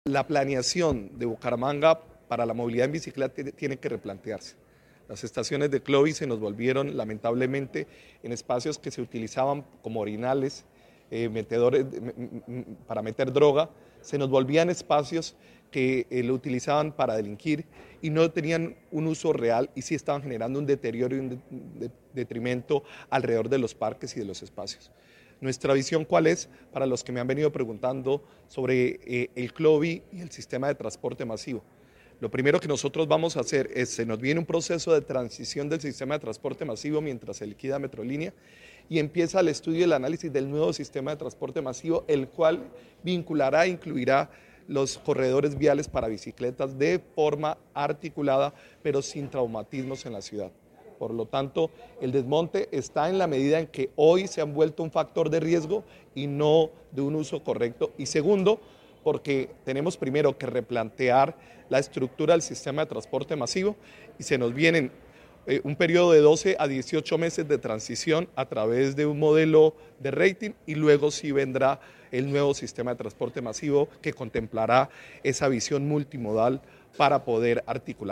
Jaime Andrés Beltrán, Alcalde de Bucaramanga